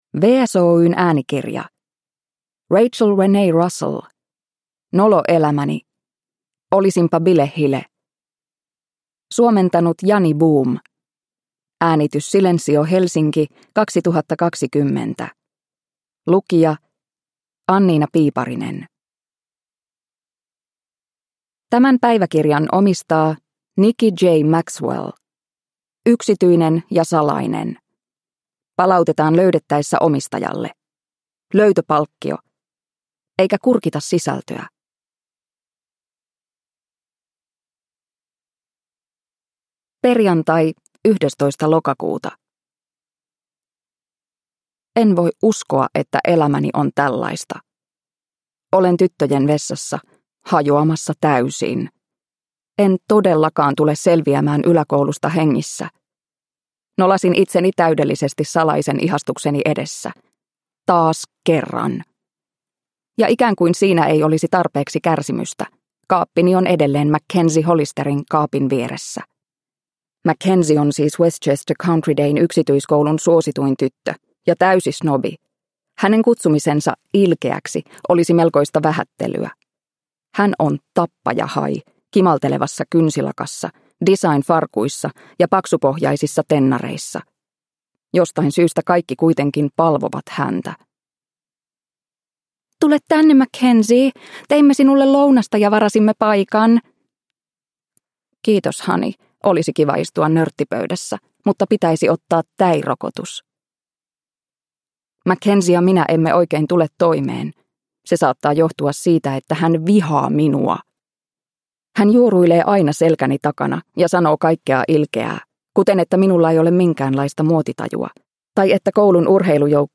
Nolo elämäni: Olisinpa bilehile – Ljudbok – Laddas ner